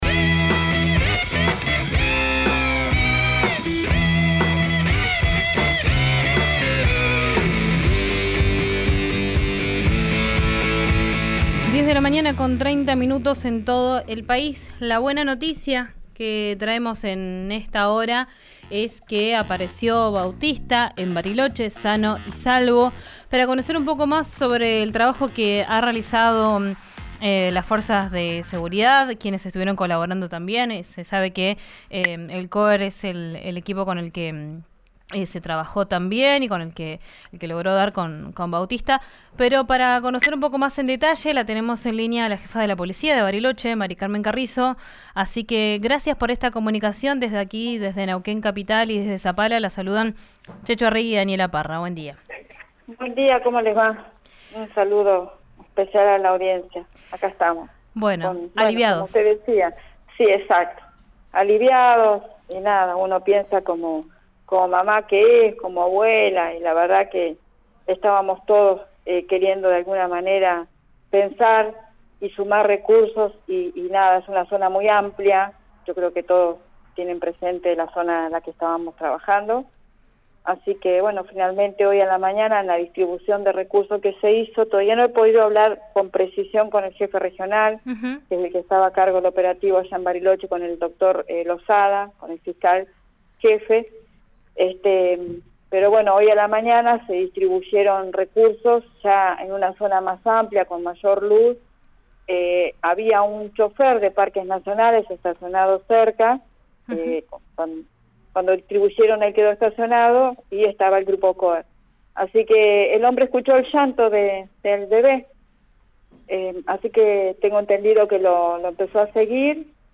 Escuchá a Mary Carmen Carrizo, jefa de la Policía, en RÍO NEGRO RADIO: